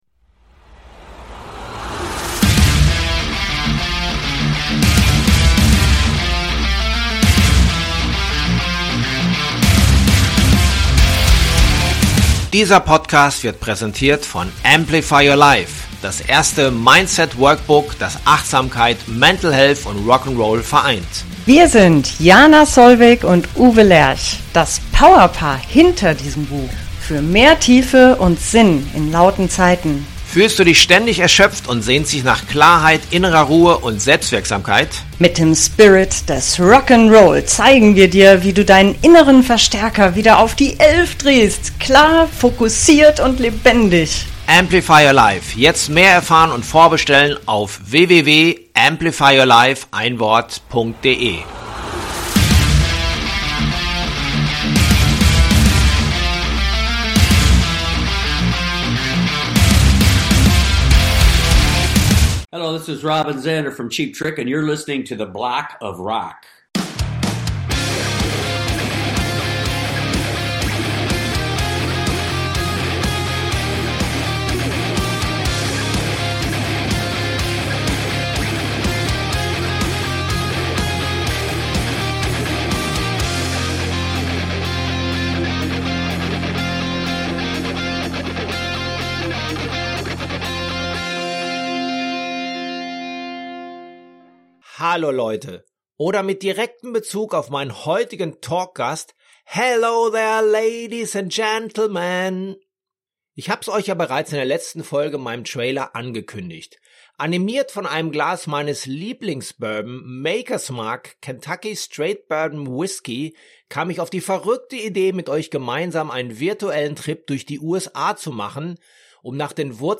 Frontman Robin Zander empfing mich zum Interview.